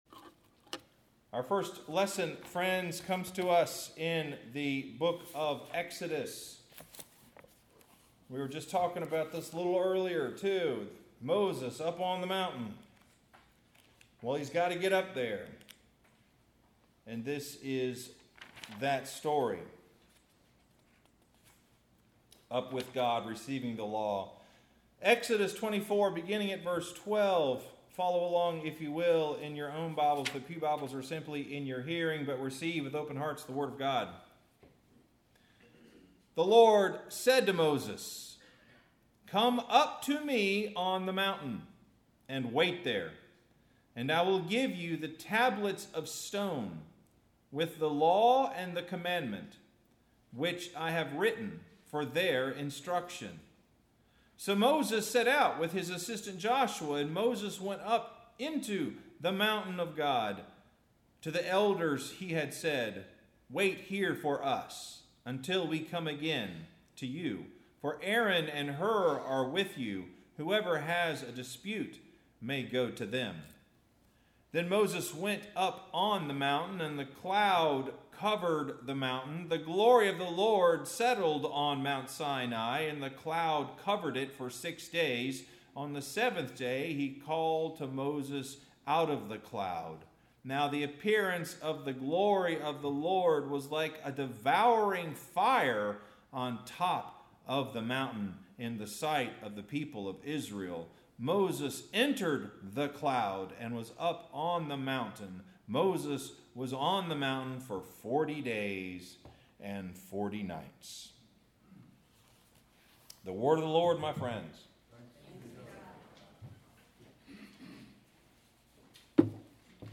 Sermon – The Message We Have Received